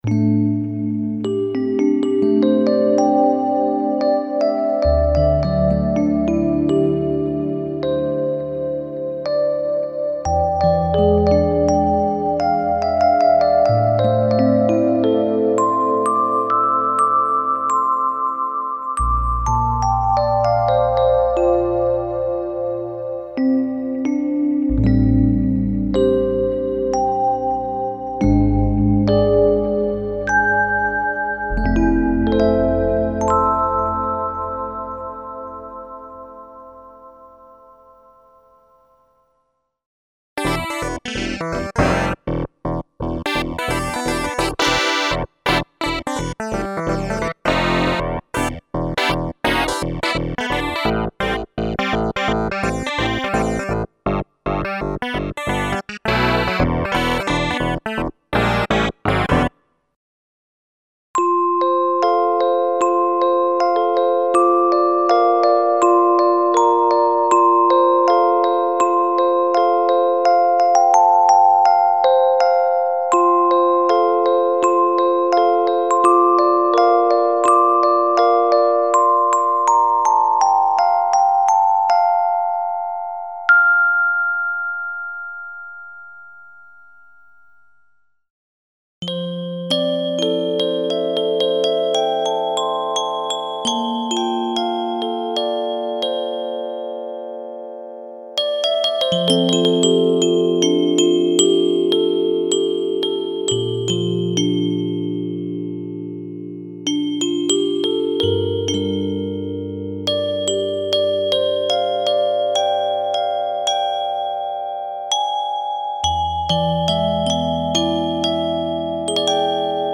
Electric pianos, clavinets and FM piano emulations for various music styles.
Info: All original K:Works sound programs use internal Kurzweil K2661 ROM samples exclusively, there are no external samples used.